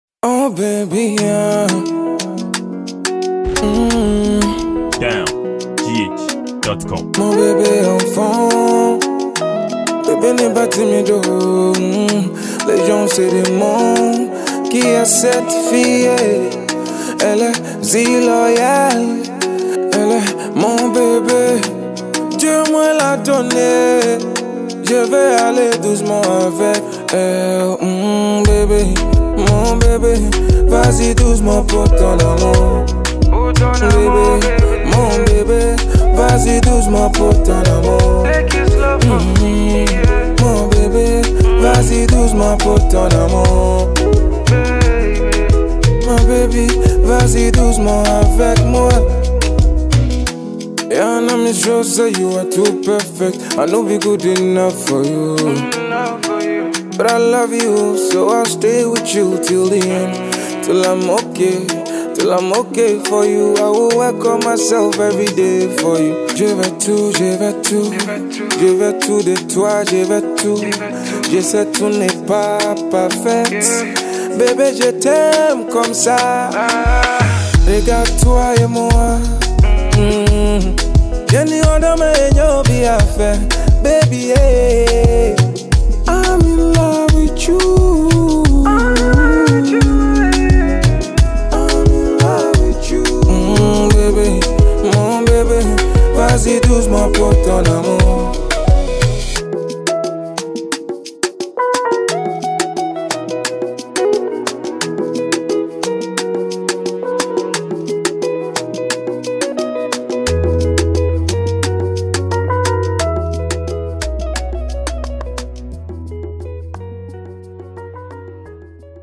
afrobeats highlife banger